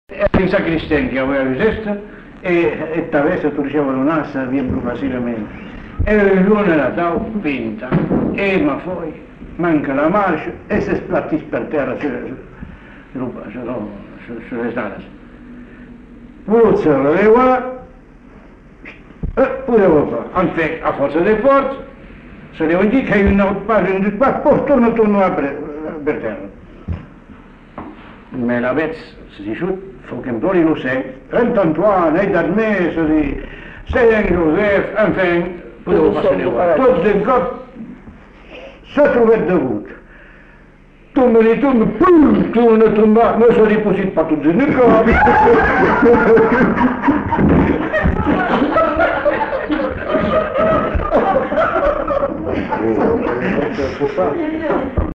Lieu : Uzeste
Genre : conte-légende-récit
Type de voix : voix d'homme
Production du son : parlé